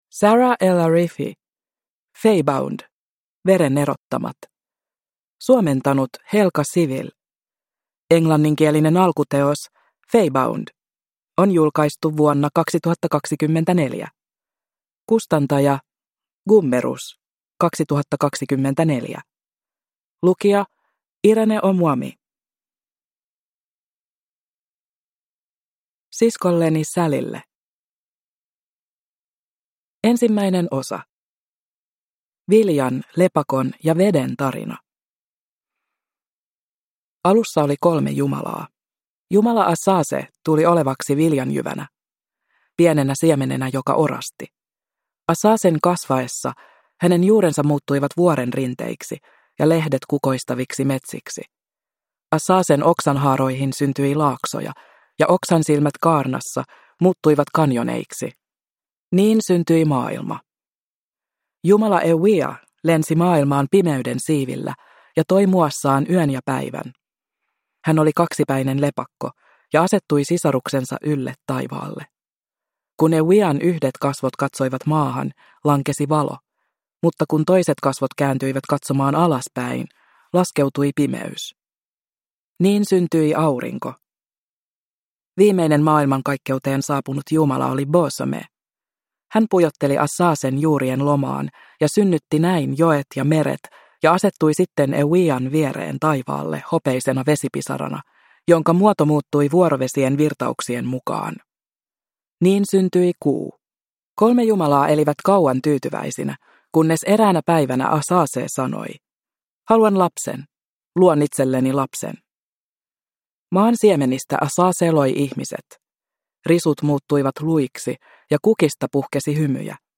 Faebound - Veren erottamat (ljudbok) av Saara El-Arifi